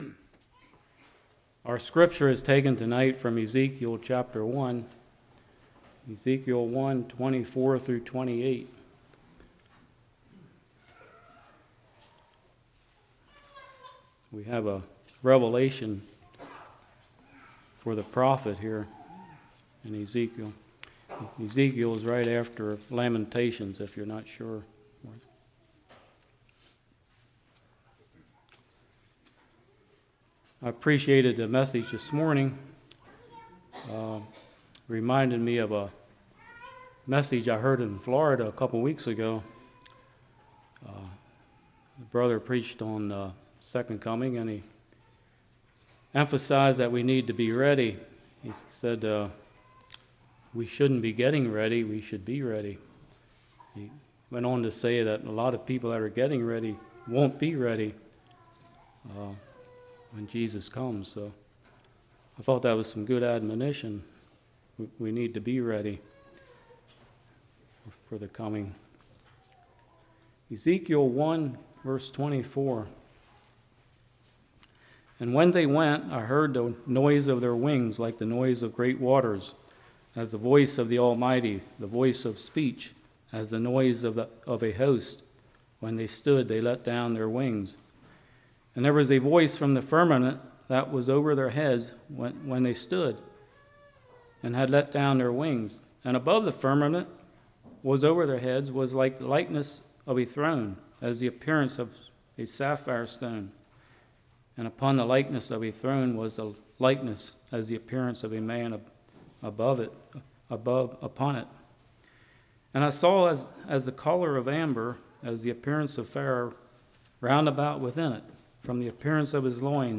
Ezekiel 1:24-28 Service Type: Evening Where did God come from?